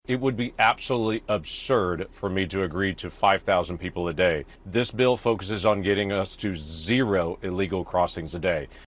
CLICK HERE to listen to commentary from Senator Lankford.